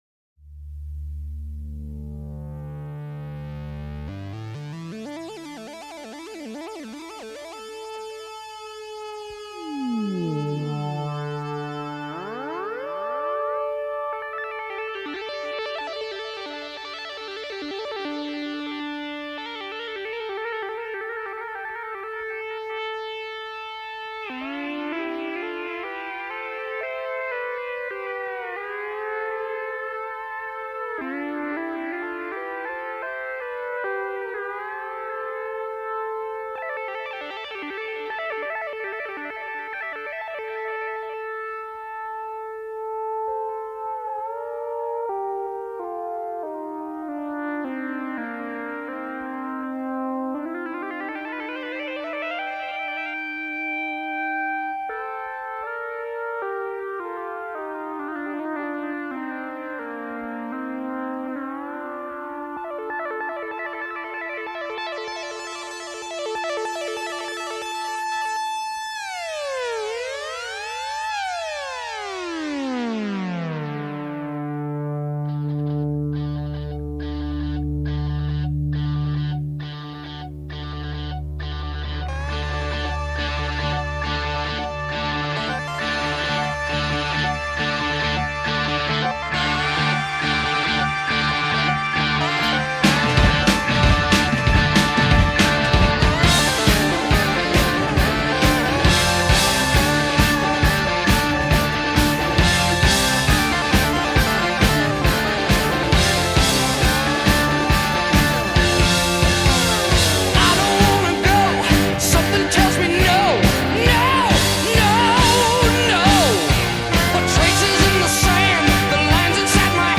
Hard Rock, Heavy Metal